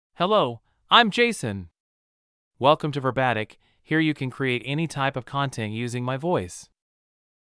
Jason — Male English (United States) AI Voice | TTS, Voice Cloning & Video | Verbatik AI
Jason is a male AI voice for English (United States).
Voice sample
Listen to Jason's male English voice.
Male
Jason delivers clear pronunciation with authentic United States English intonation, making your content sound professionally produced.